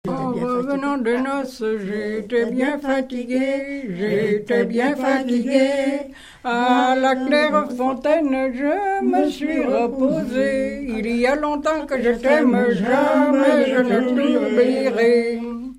gestuel : à marcher
Pièce musicale inédite